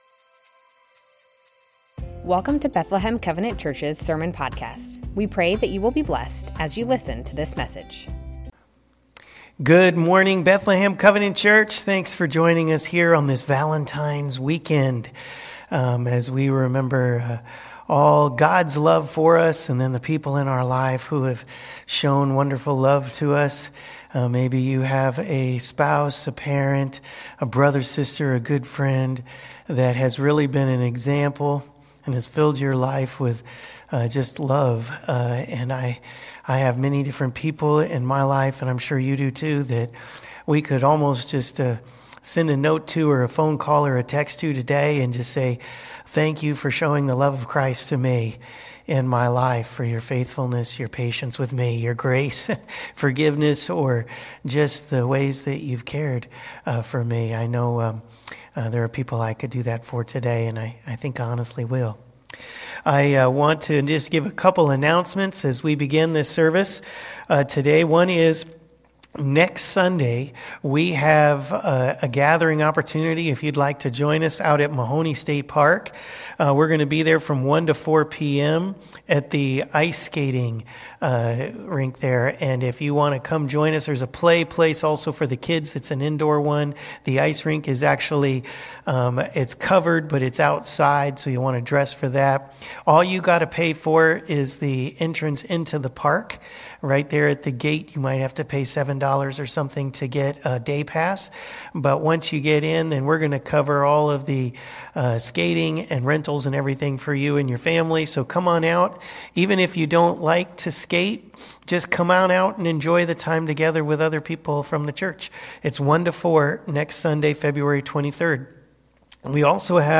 Bethlehem Covenant Church Sermons Matthew 5:17-37 - Jesus fulfills the law in us Feb 16 2025 | 00:33:43 1x 00:00 / 00:33:43 Subscribe Share Spotify RSS Feed Share Link Embed